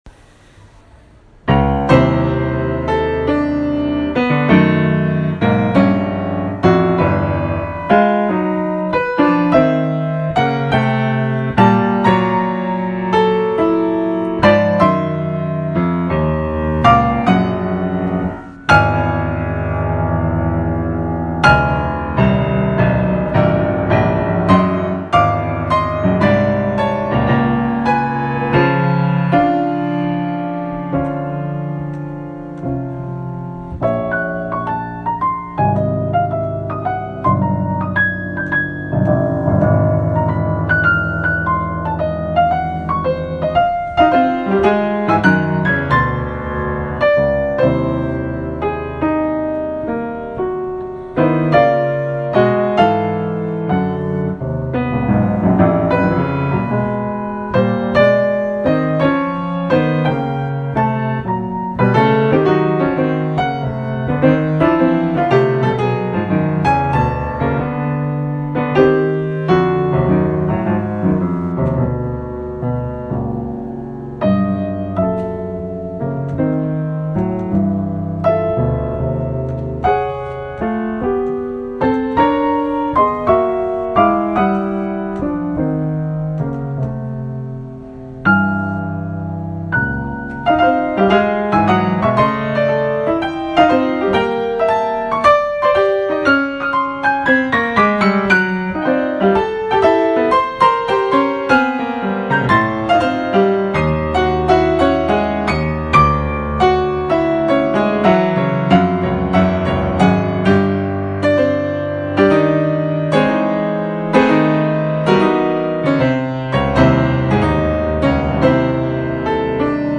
This is masterful midcentury American composition.
is straight up Boulanger/Stravinsky idiom.